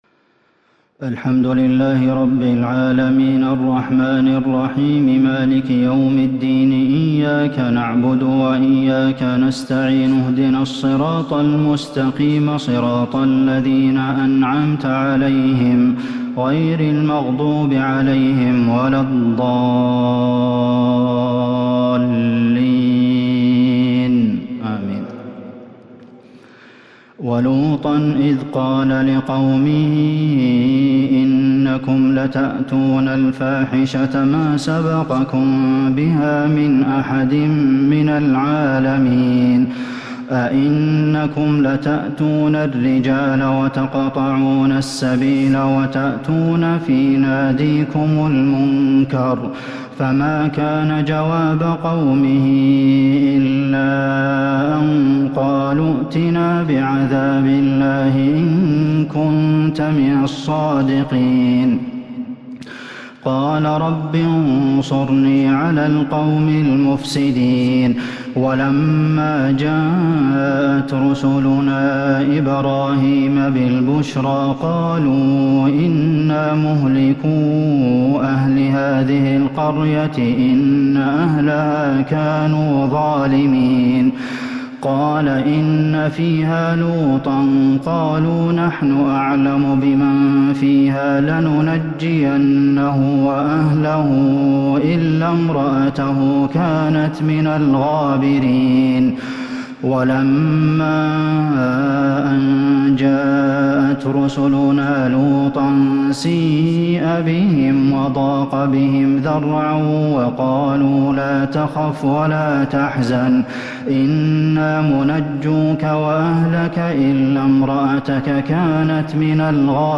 صلاة التراويح l ليلة 24 رمضان 1442 l من سورتي العنكبوت (28) إلى الروم (39) taraweeh prayer The 24rd night of Ramadan 1442H | from surah Al-Ankabut and Ar-Room > تراويح الحرم النبوي عام 1442 🕌 > التراويح - تلاوات الحرمين